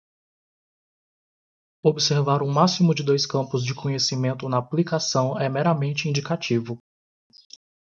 /a.pli.kaˈsɐ̃w̃/